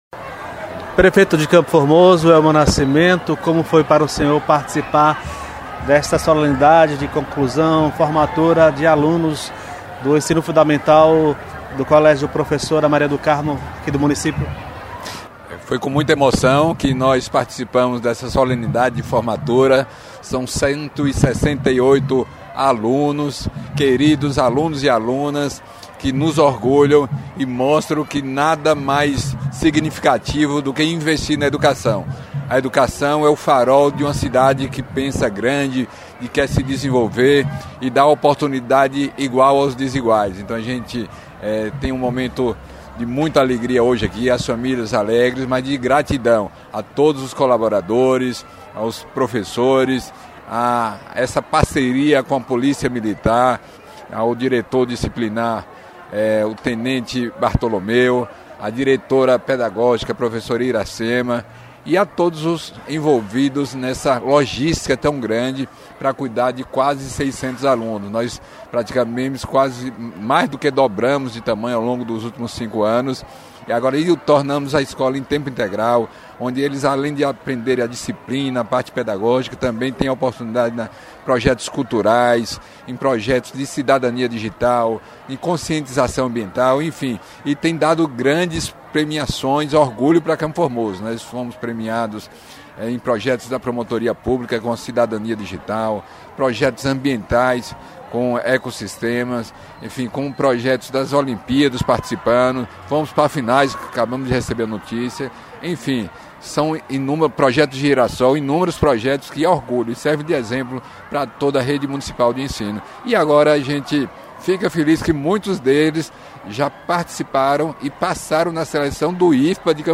Prefeito de Campo Formoso, Elmo Nascimento – Solenidade de formandos do ensino fundamental da escola Maria do Carmo